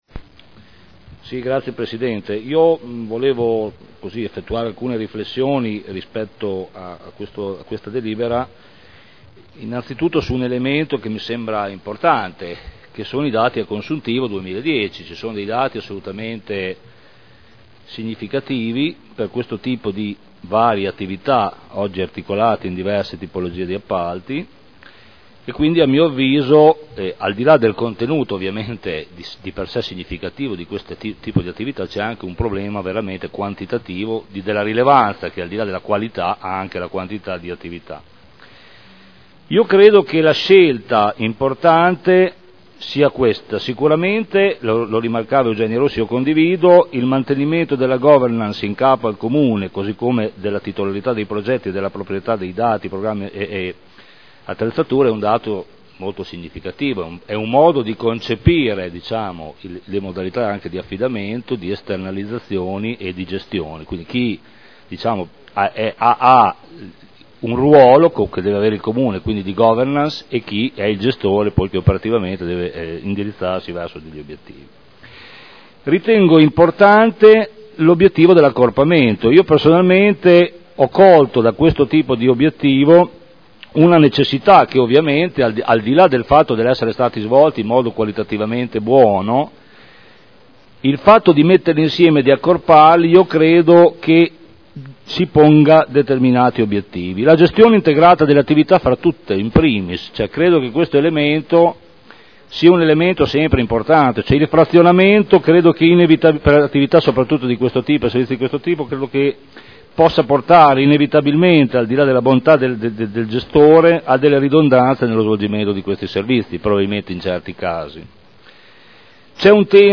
Giancarlo Campioli — Sito Audio Consiglio Comunale
Dibattito su proposta di deliberazione. Appalto per la gestione di servizi ed attività orientati alla coesione sociale – Periodo dal 01.01.2012 al 31.12.2014